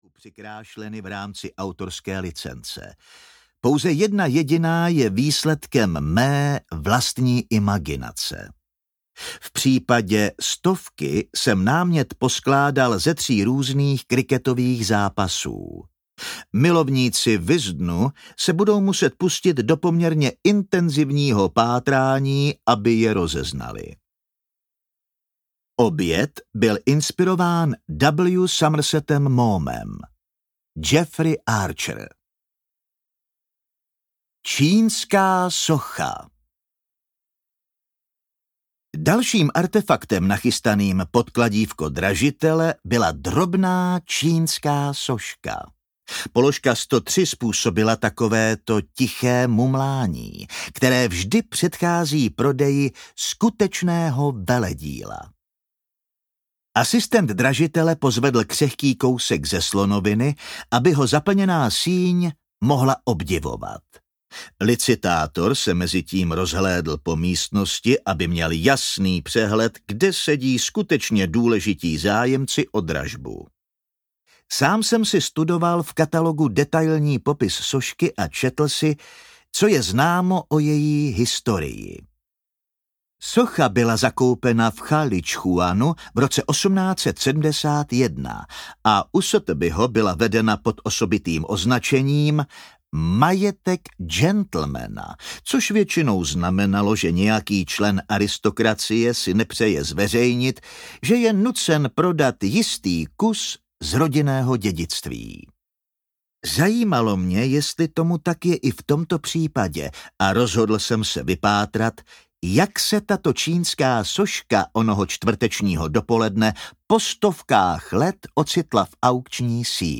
Toulec plný šípů audiokniha
Ukázka z knihy
toulec-plny-sipu-audiokniha